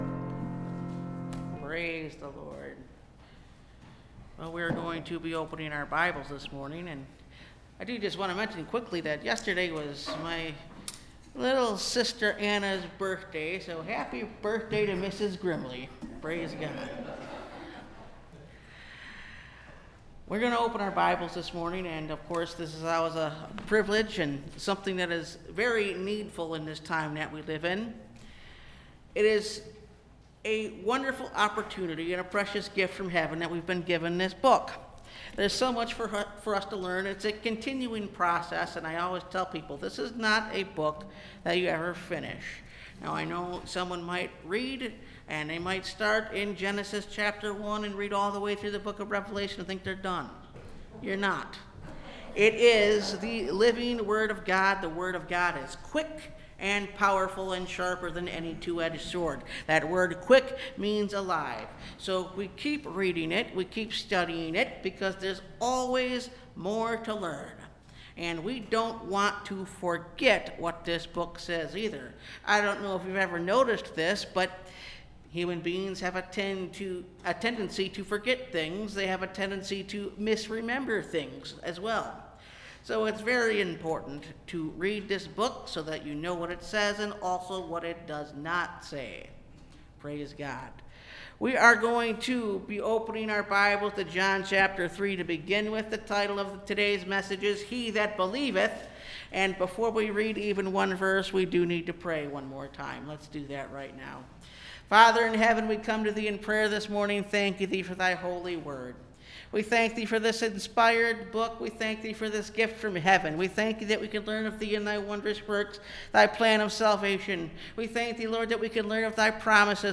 He That Believeth (Message Audio) – Last Trumpet Ministries – Truth Tabernacle – Sermon Library